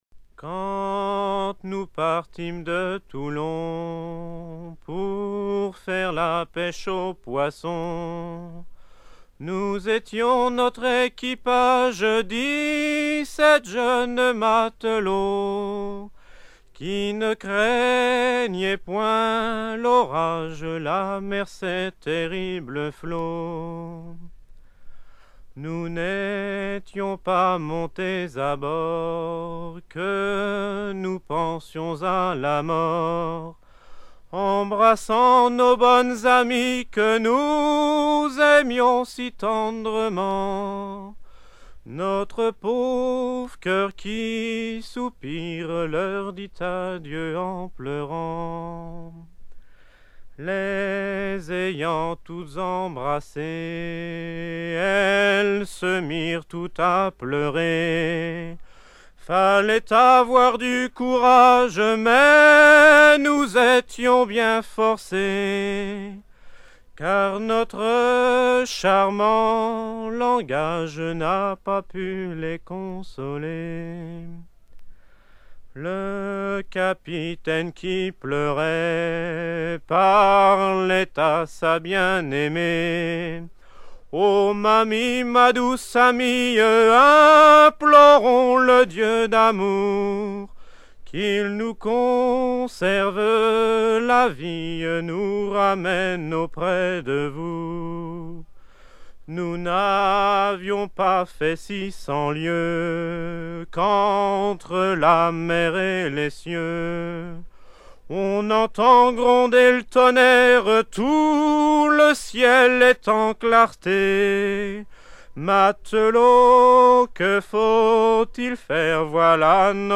Genre strophique
Chants de marins traditionnels